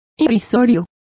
Complete with pronunciation of the translation of laughable.